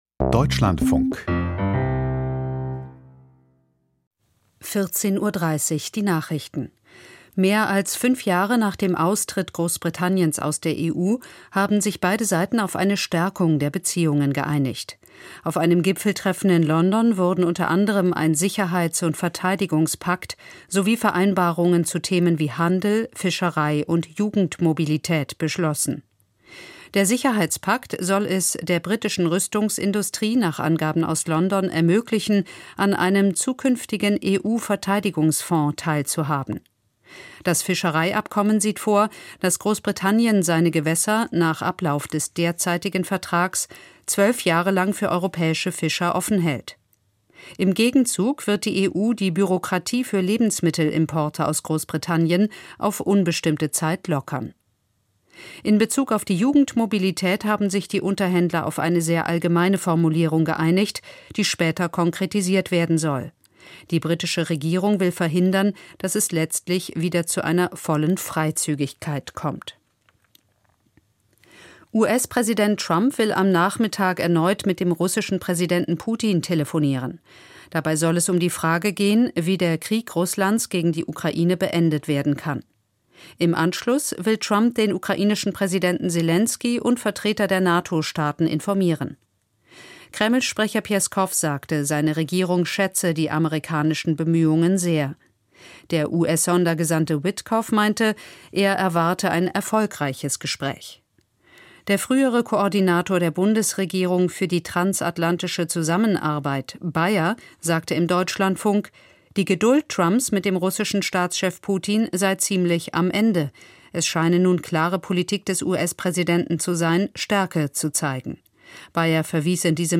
Die Nachrichten vom 19.05.2025, 14:30 Uhr
Aus der Deutschlandfunk-Nachrichtenredaktion.